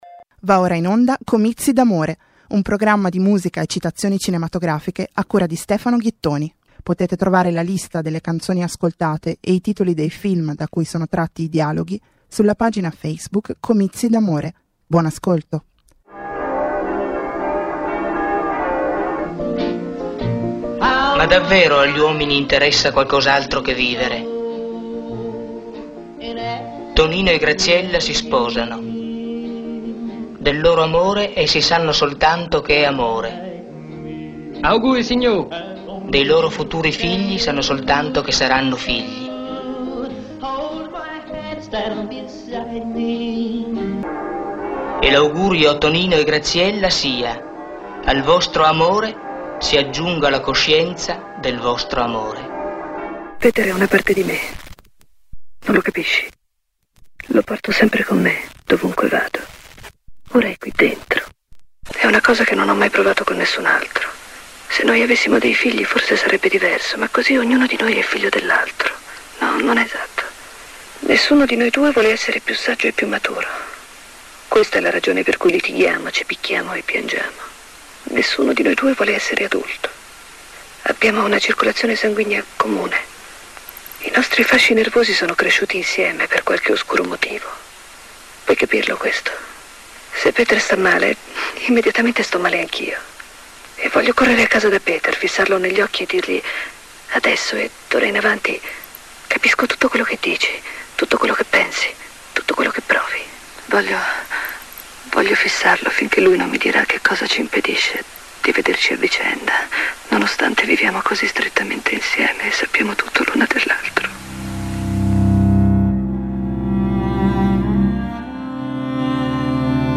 Quaranta minuti di musica e dialoghi cinematografici trasposti, isolati, destrutturati per creare nuove forme emotive di ascolto.